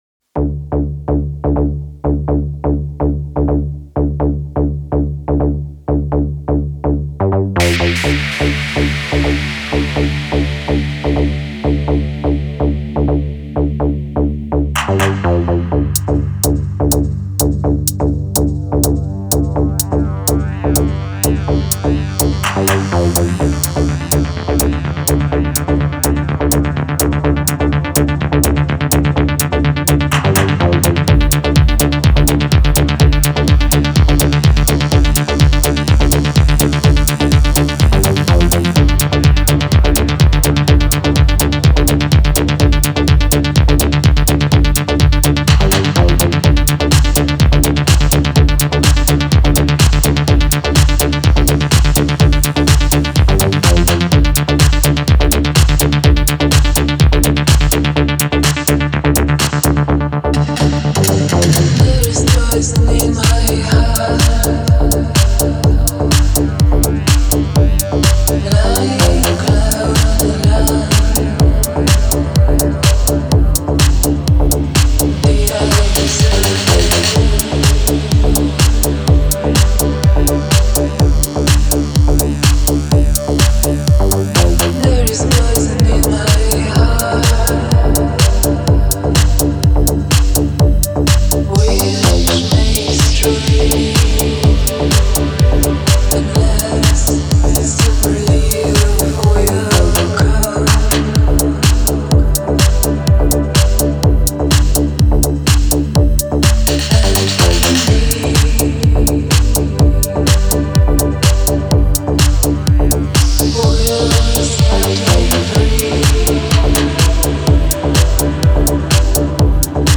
Berlin-based duo